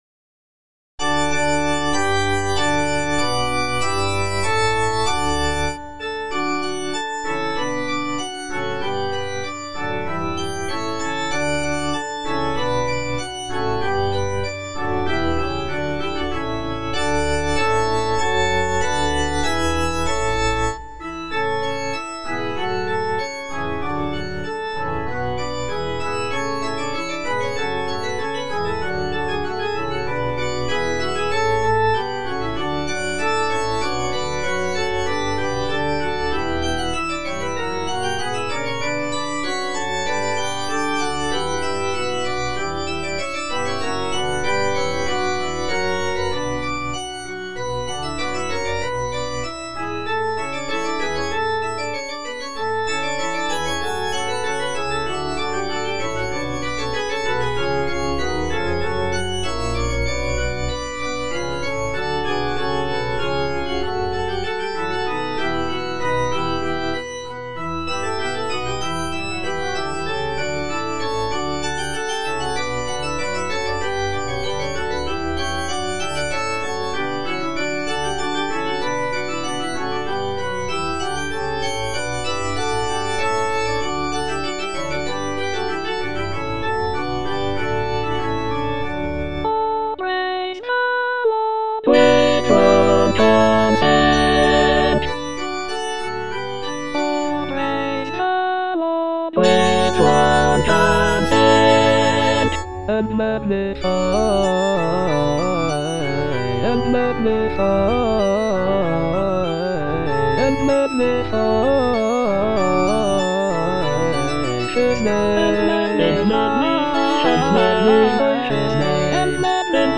(A = 415 Hz)
(All voices)